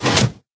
piston